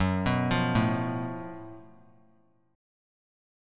Listen to F#mM7bb5 strummed